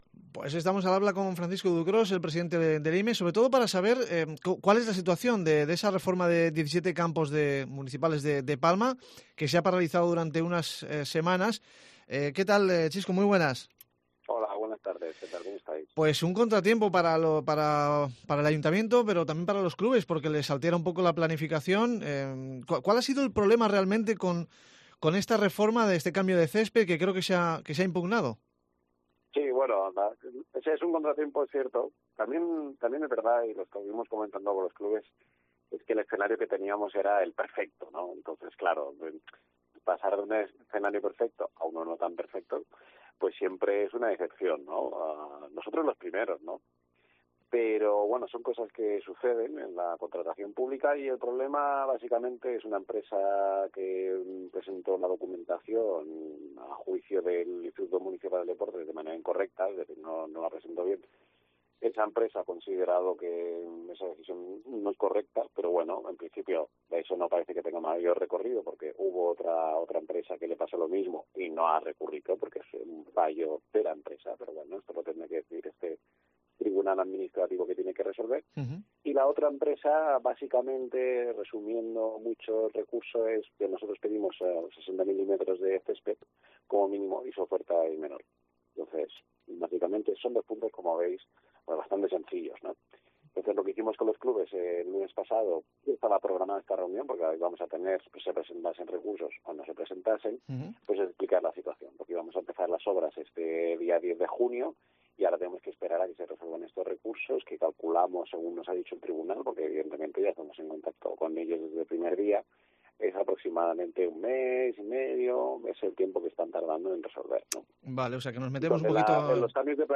Hablamos con el presidente del Instituto Municipal de Deportes de Palma sobre la reforma de 17 campos de Palma.
El responsable municipal de deportes Francisco Ducrós comenta qué ha pasado y cómo tendrán que arreglárselas los clubes mientras tanto.